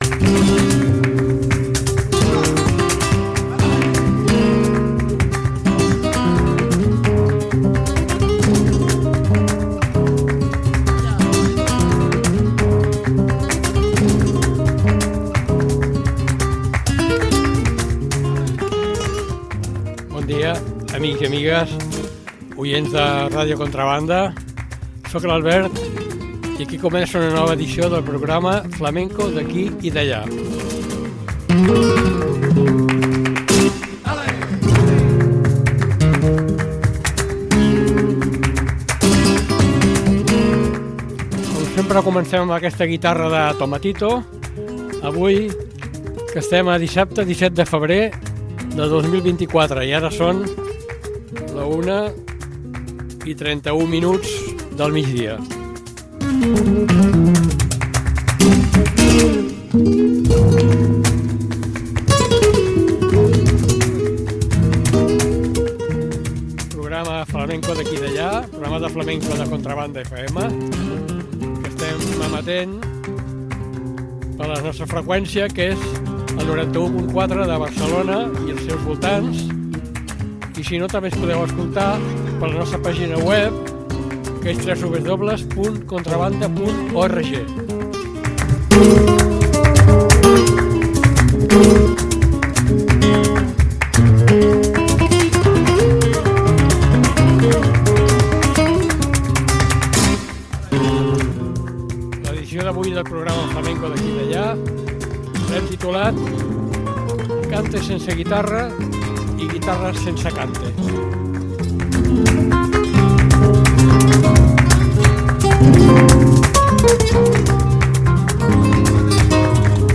CANTES SIN GUITARRA – GUITARRAS SIN CANTE
Martinetes, saetas.